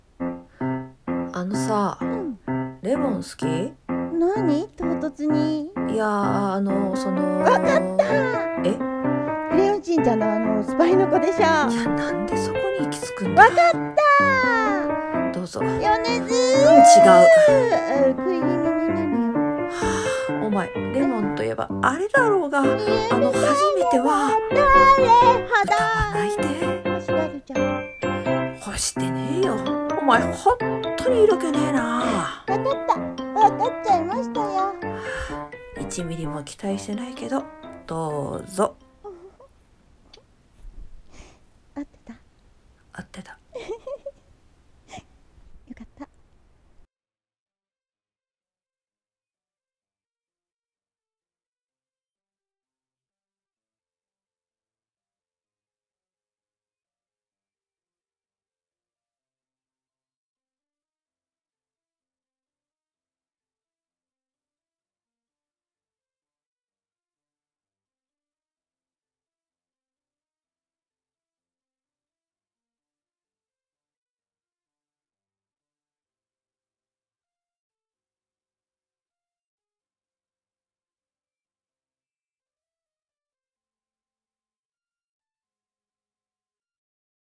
さんの投稿した曲一覧 を表示 掛け合い 二人台本【ファーストキスは】